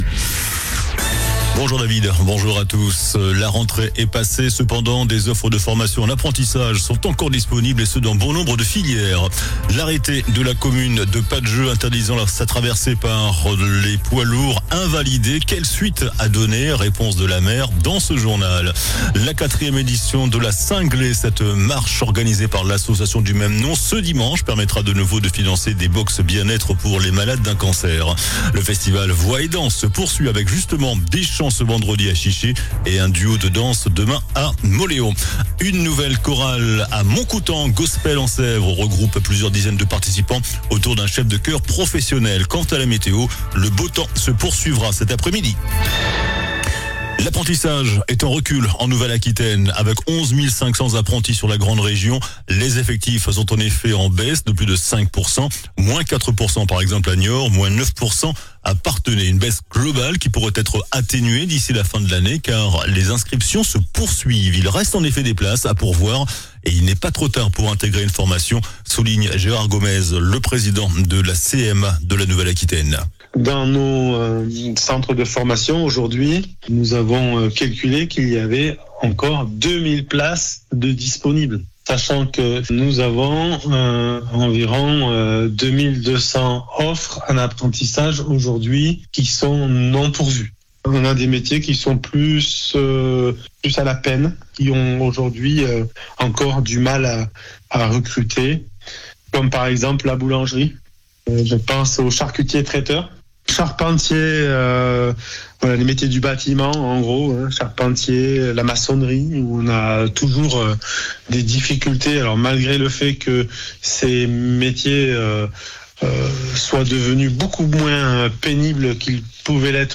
JOURNAL DU VENDREDI 10 OCTOBRE ( MIDI )